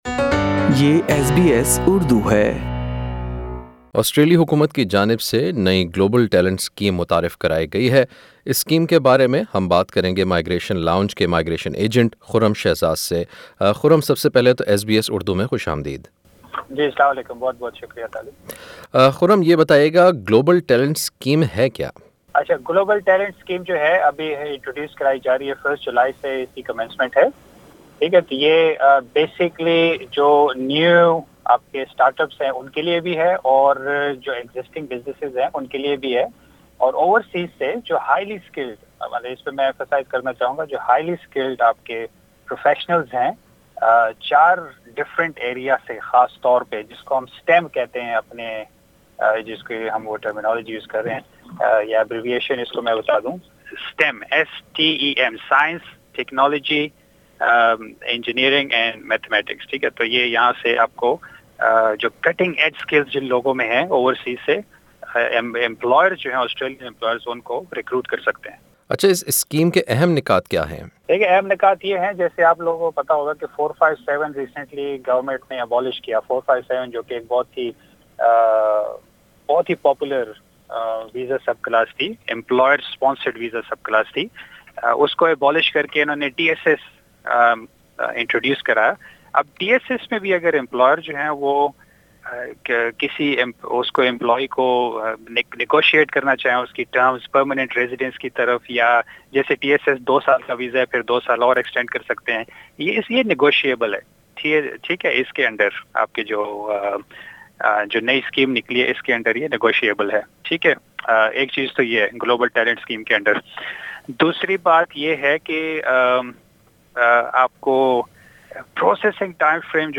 (Audio Interview)